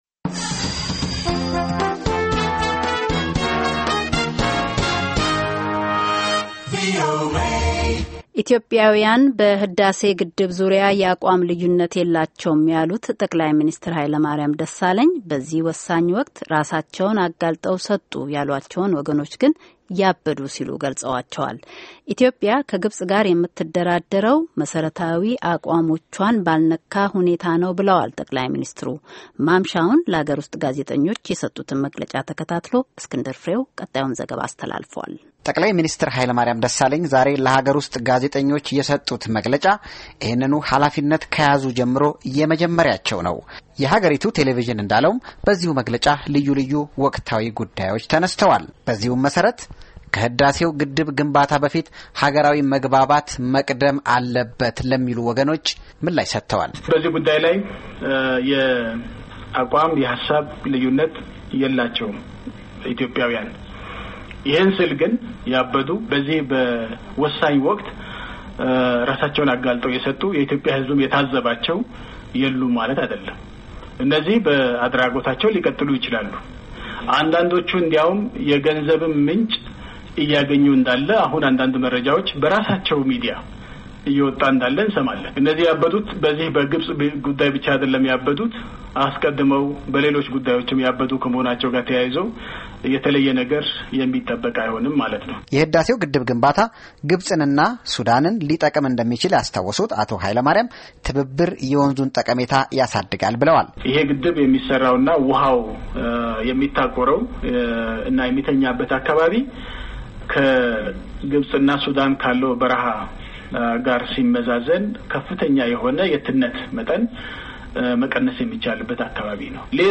Hailemariam Desalegn, Pm, 1st press conference, Addis Ababa, 06/28/13